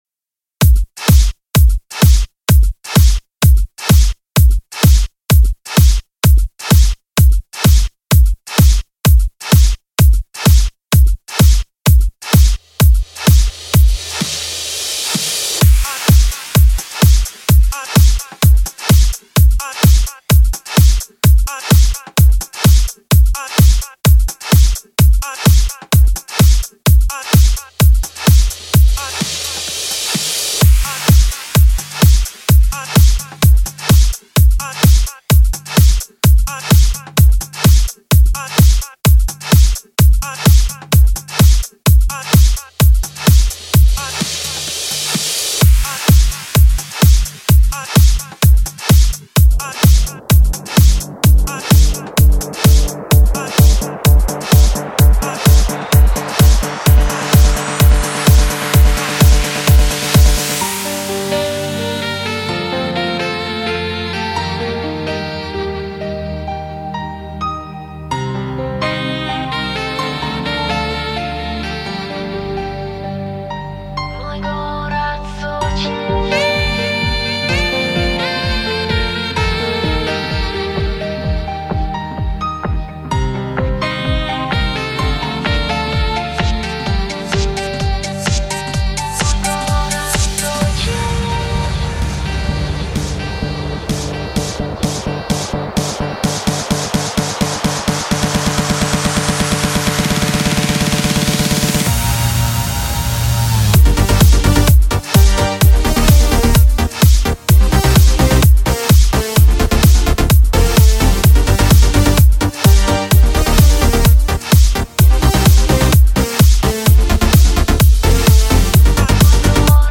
Стиль: Electro House / Electro